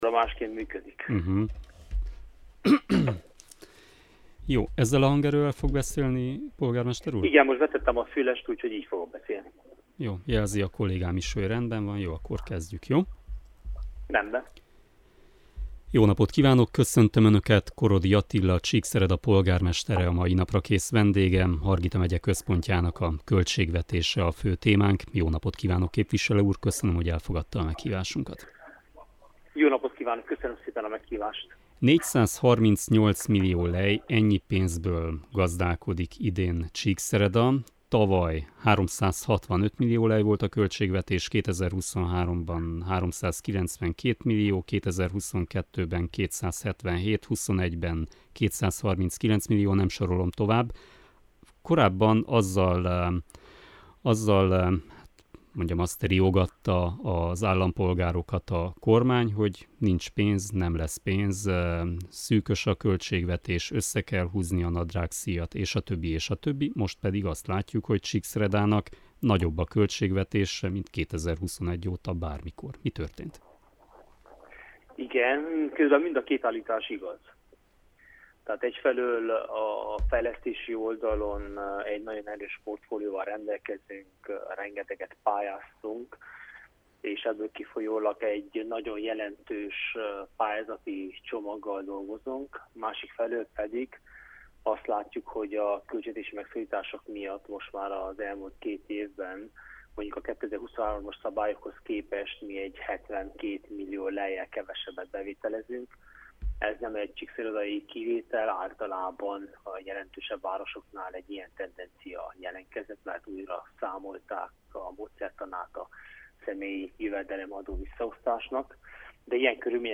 Ő a mai Naprakész vendége. Csíkszereda fejlődési lehetőségeiről, az idei év kihívásairól beszélgetünk.